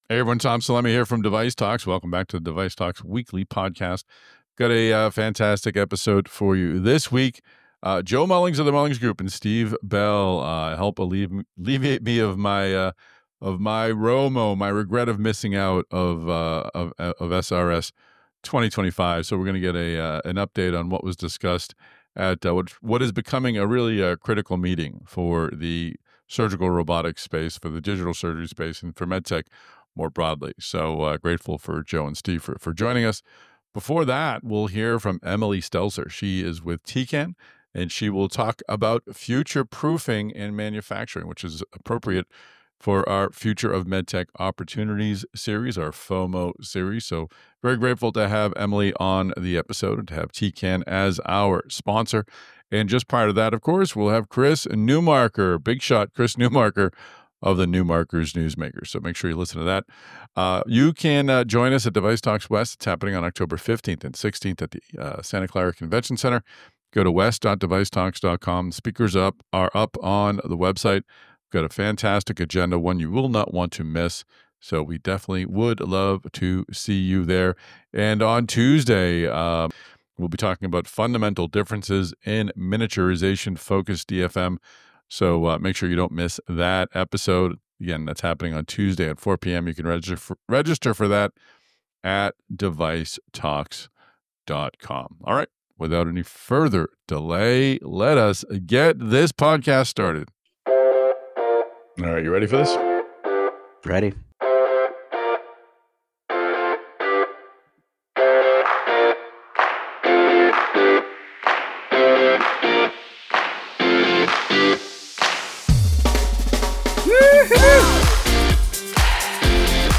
SRS Round Table